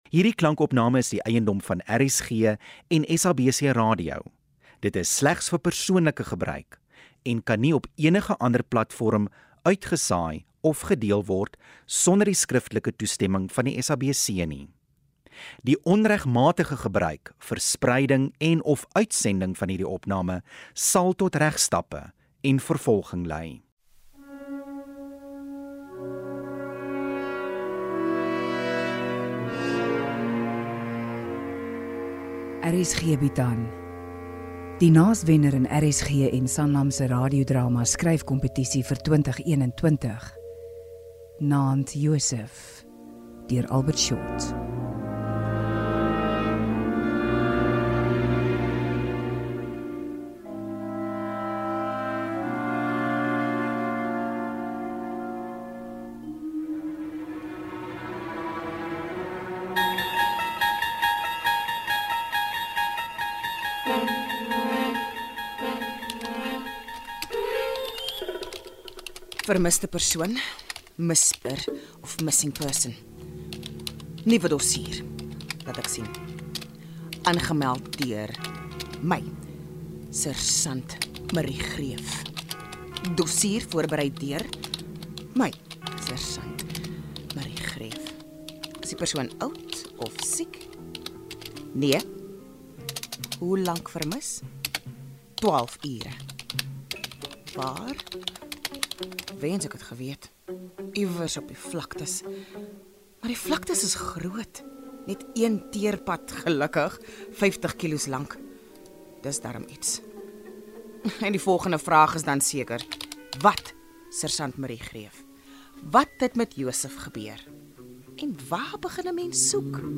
Dis donker komedie op sy beste – ‘n spookstorie met ‘n kinkel – en die karakters is so kleurvol soos wat jy op ‘n uitgestrekte vlakte sal kry.